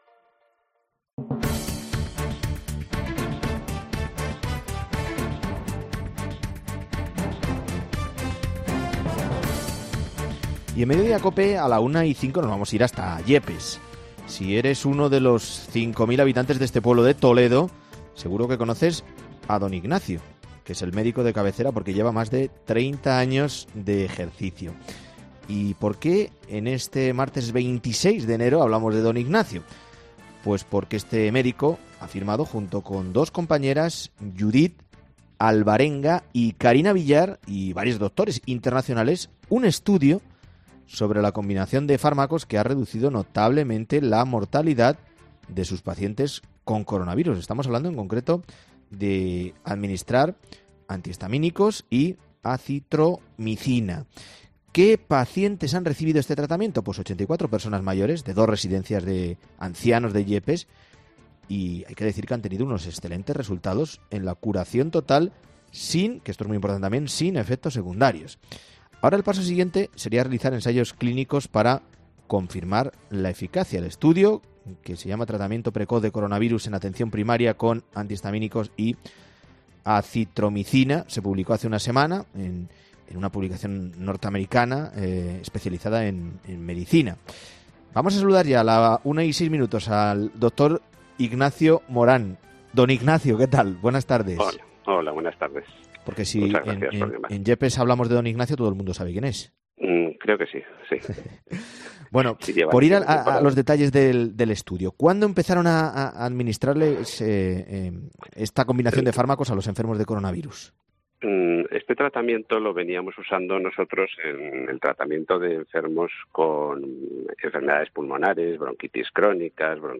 El doctor ha contado que el tratamiento lo habían administrado desde hace años a pacientes con enfermedades pulmonares.